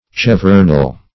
Search Result for " chevronel" : The Collaborative International Dictionary of English v.0.48: Chevronel \Chev"ron*el\, n. (Her.) A bearing like a chevron, but of only half its width.